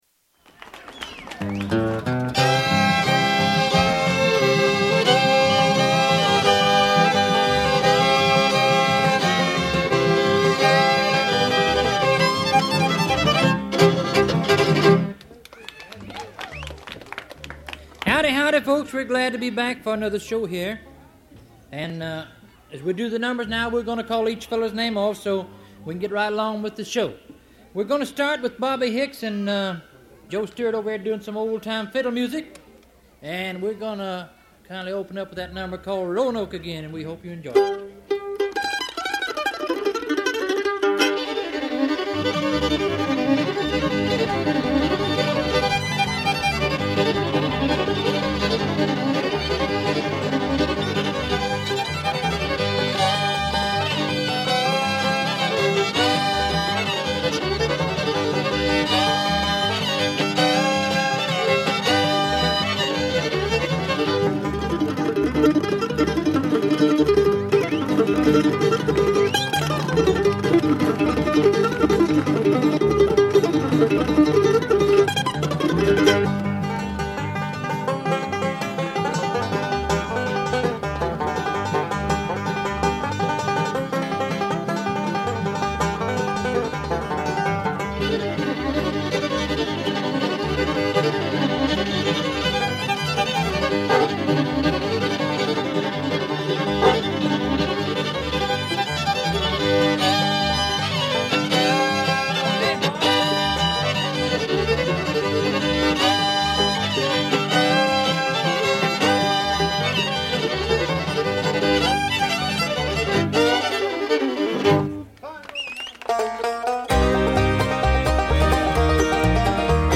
Genre : Bluegrass